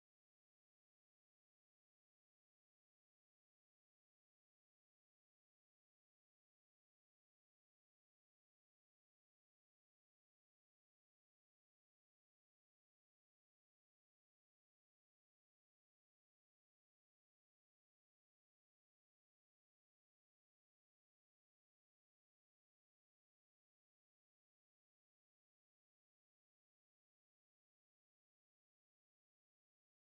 Kindertänze: Jäger und Mädchen
Tonart: C-Dur
Taktart: 3/4
Tonumfang: Oktave
Besetzung: vokal